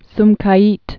(smkī-ēt) or Sum·ga·it (-gī-ēt)